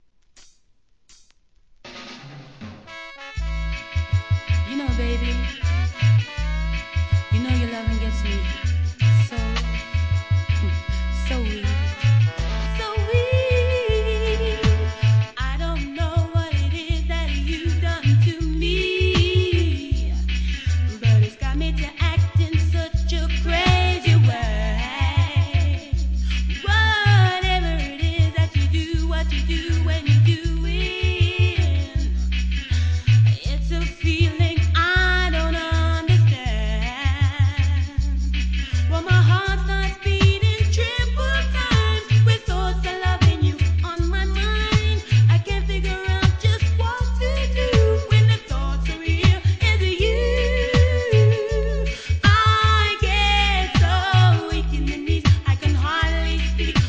REGGAE
DANCE HALL MIX
RAGGA MIX